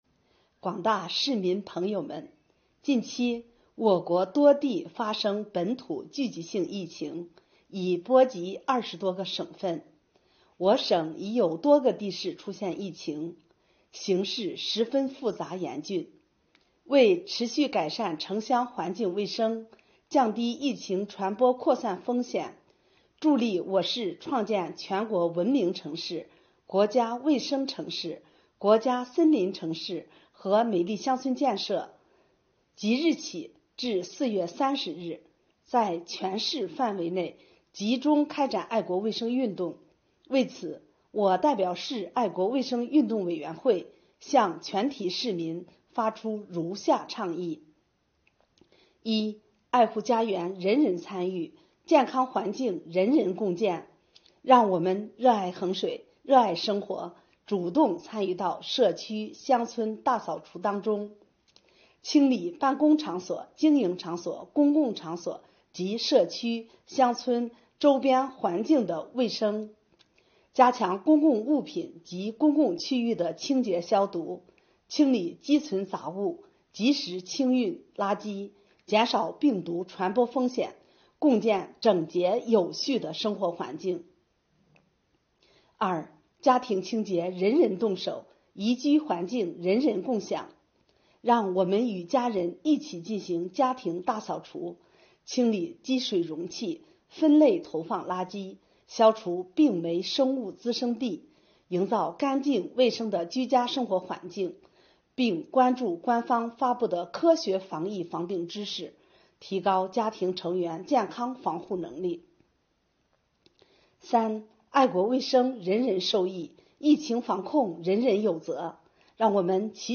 衡水市副市长崔海霞就开展春季爱国卫生运动向全市人民发出倡议